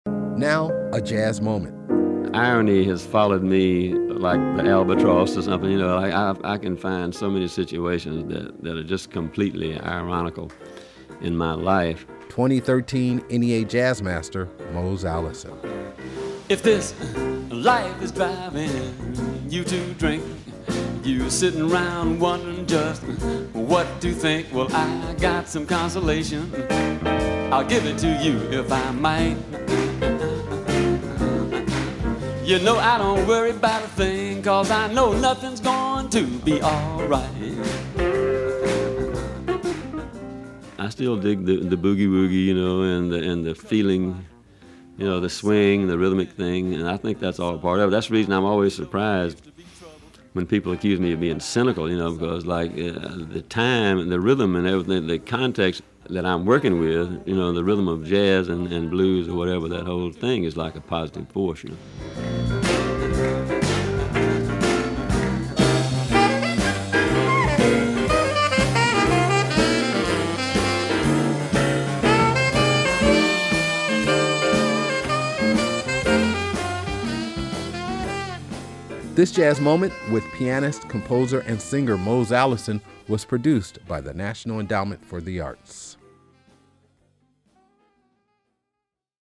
Excerpt of “I Don't Worry About A Thing” written and performed by Mose Allison, from the album, Lessons in Living: Live from Montreux, used courtesy of Atlantic / Rhino Records, and used by permission of Audre Mae Music Inc. (BMI).
Audio Credit: Excerpt of Allison from an interview with Ben Sidran featured on the cd, Talking Jazz Volume 19, used courtesy of Ben Sidran.